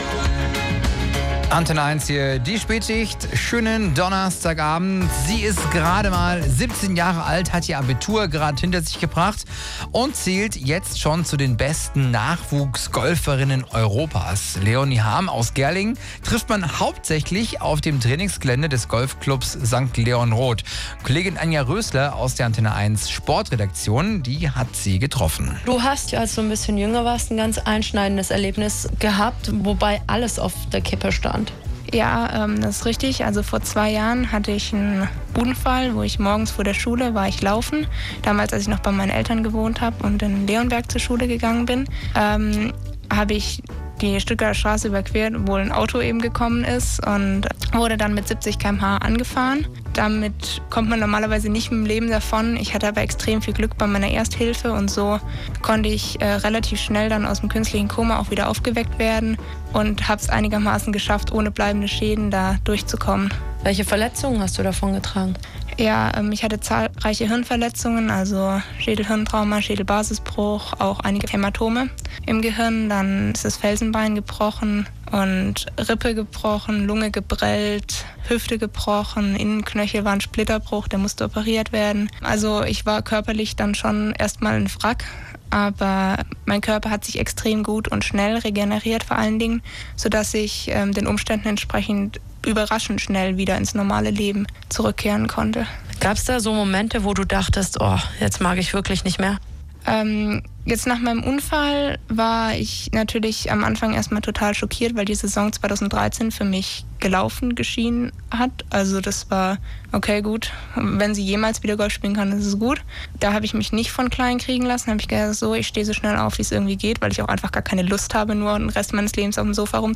Radiointerview
Interview